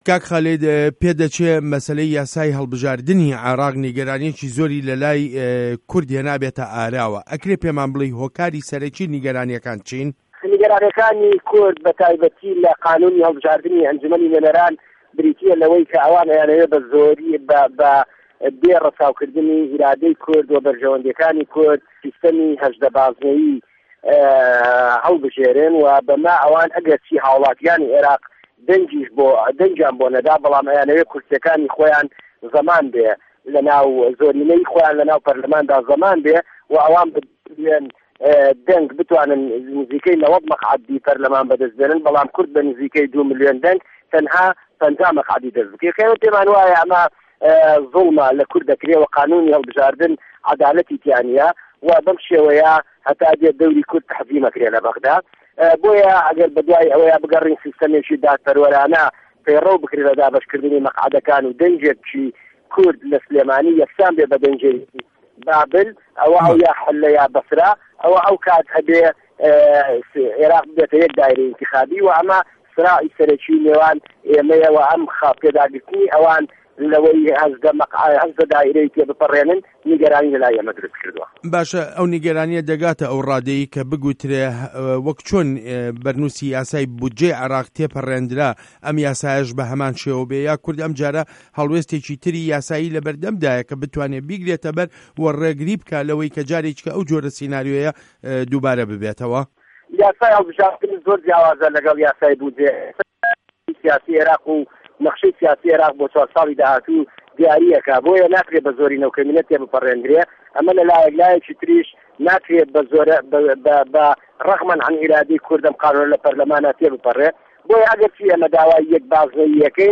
وتووێژ له‌گه‌ڵ خالید شوانی